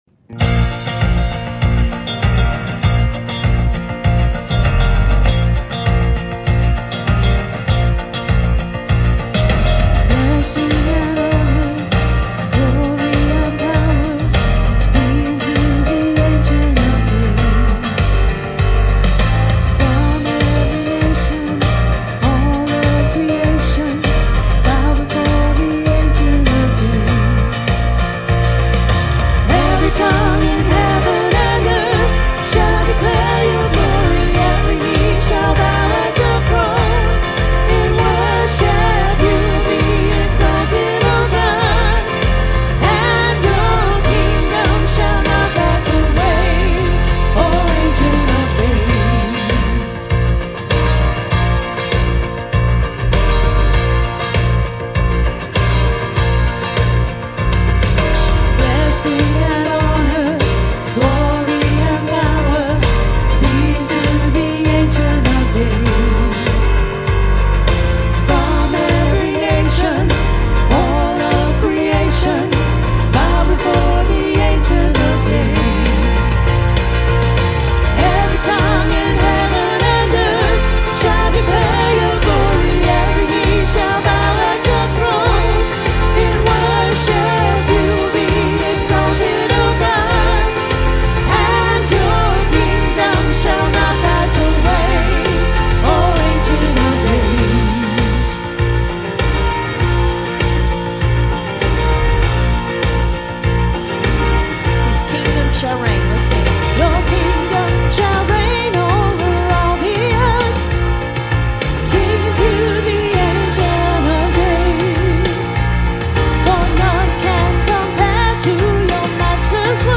Praise Team Audio